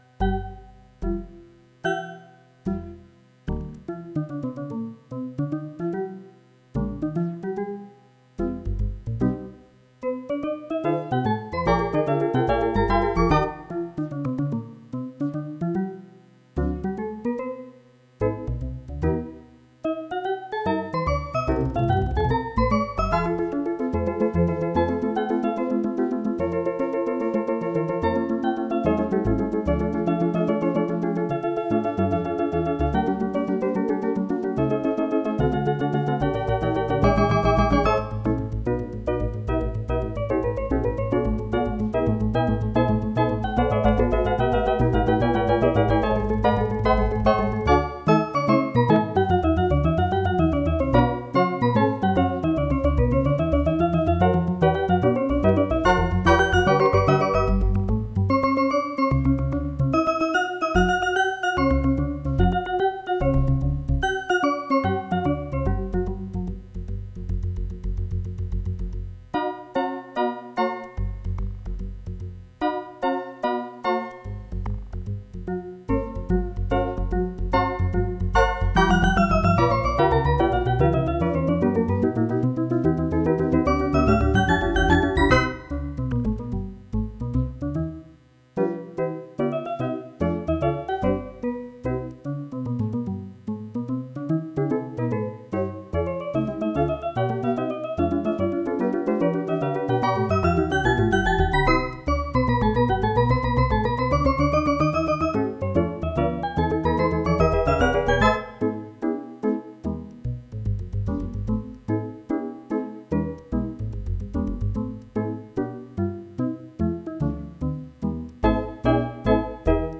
'Mussorgsky Sonata' - Piece in 4 parts using 'Trashy Toy Piano' preset